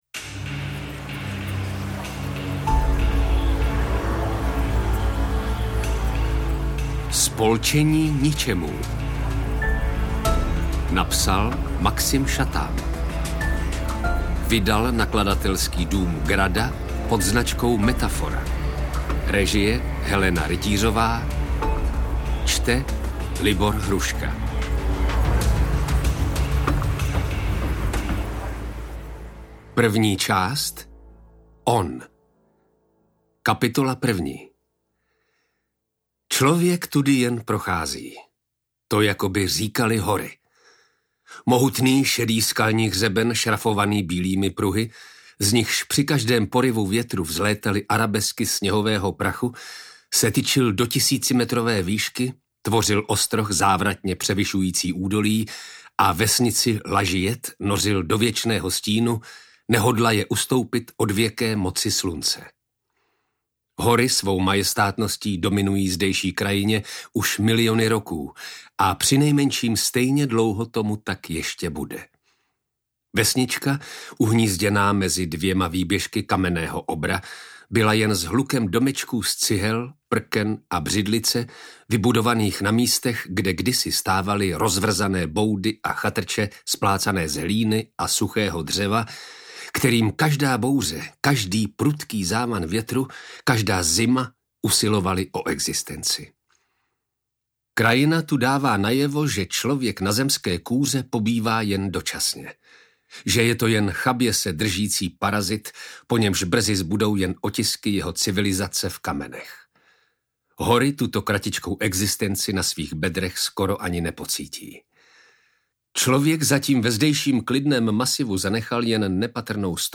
Ukázka z audioknihy Spolčení ničemů
Takřka hororový thriller v podání Libora Hrušky
Bezmála 450 stran temného čtení vydalo na 14 hodin záznamu, který si užijete v dramatickém přednesu českého filmového a divadelního herce, dabéra, jenž propůjčil hlas například Samuelu L. Jacksonovi, Kurtu Russellovi nebo Patrickovi Swayzemu, držitele Ceny Františka Filipovského Libora Hrušky.